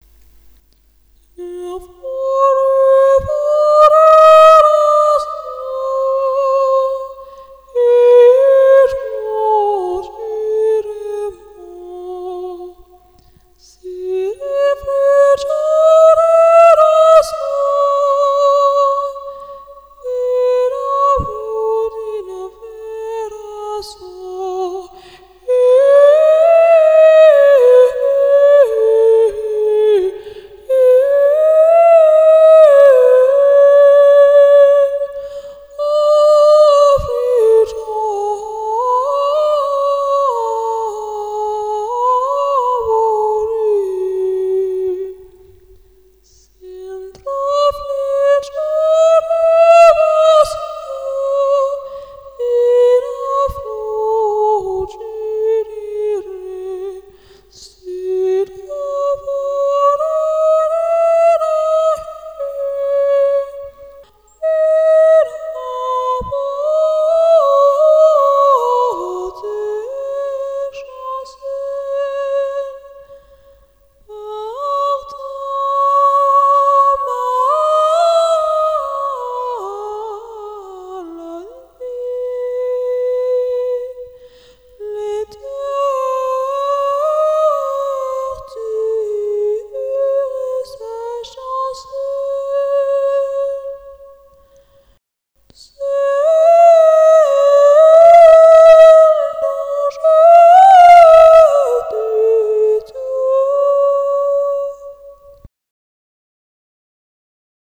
Genre: Medieval Folk / Ambient